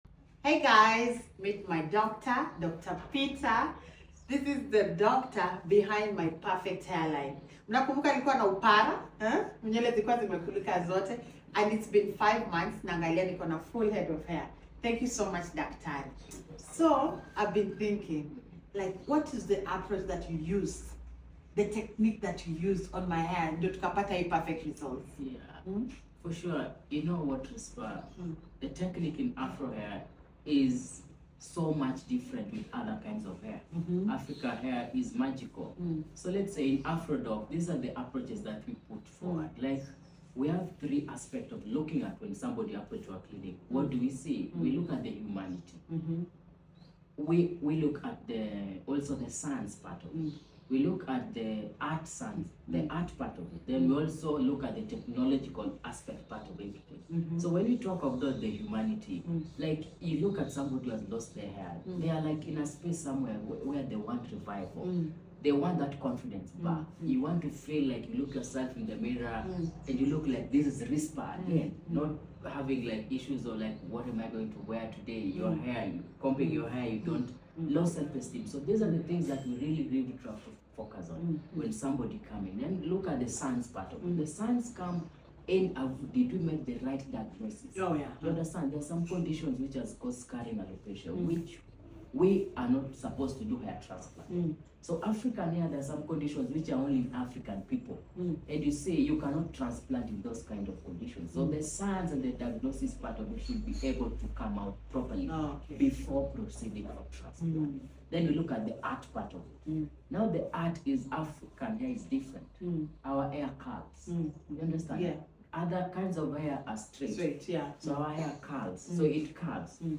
Depth conversation about African hair